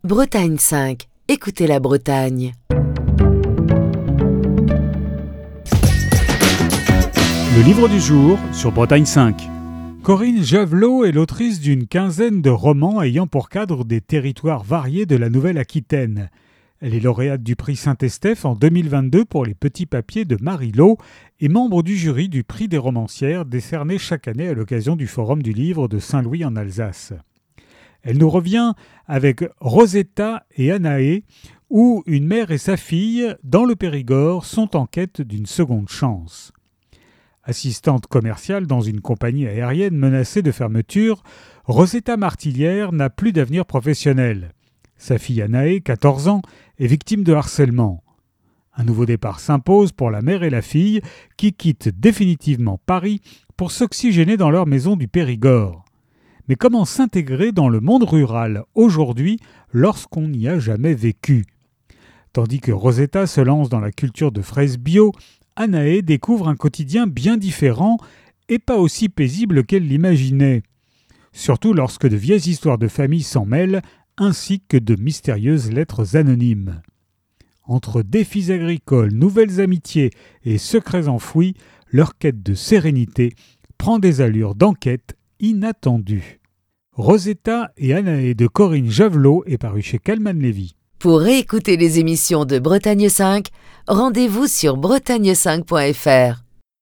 Chronique du 14 juillet 2025.